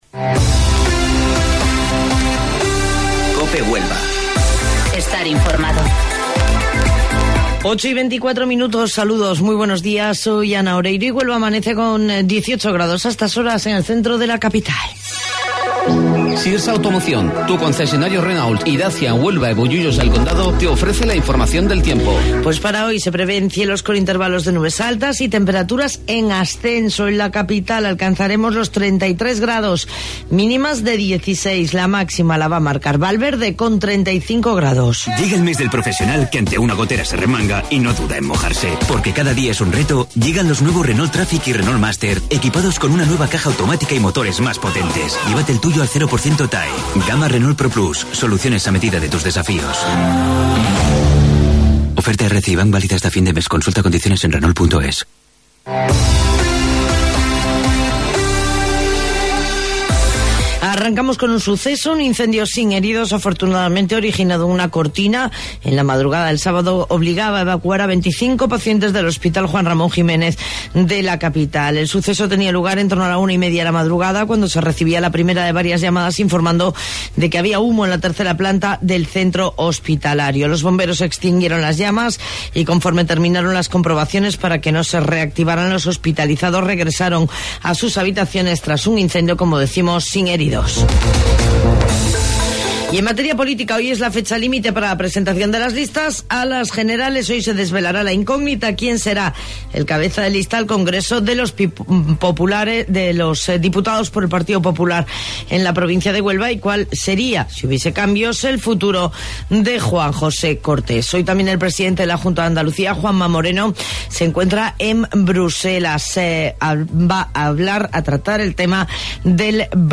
AUDIO: Informativo Local 08:25 del 7 de Octubre